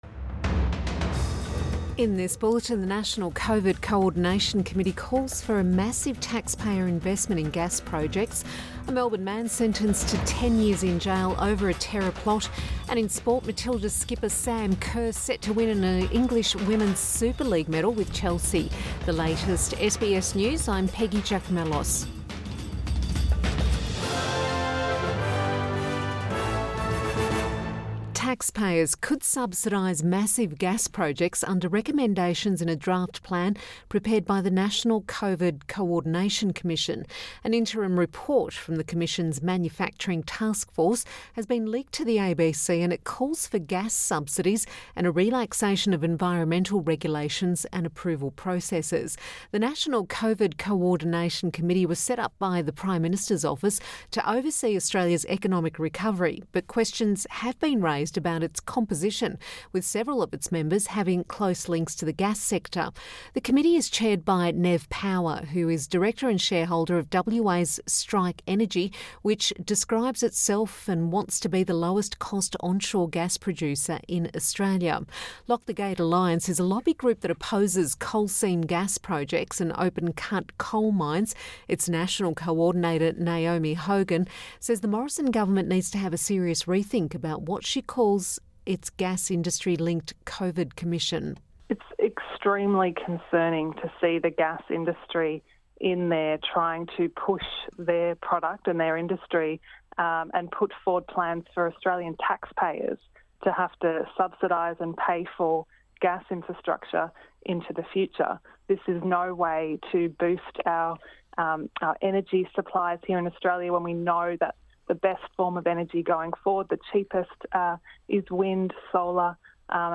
PM bulletin 21 May 2020